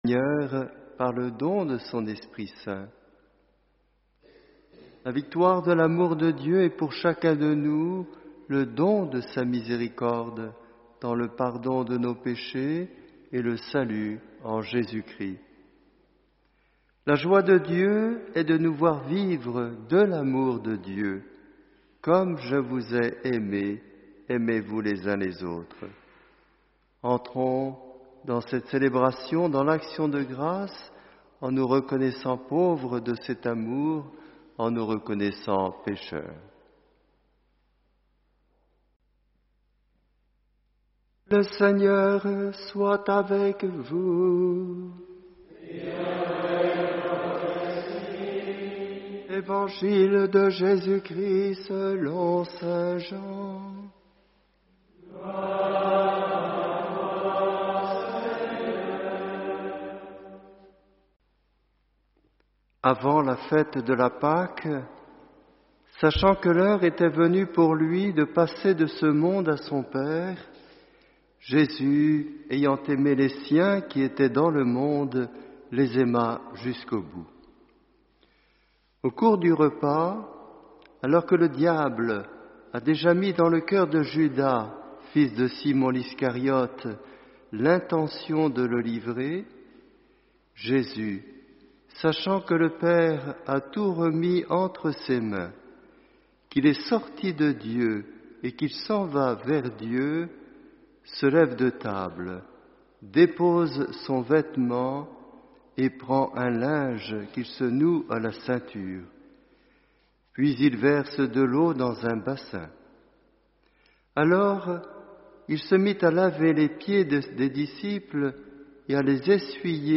JEUDI-SAINT MESSE DE LA CÈNE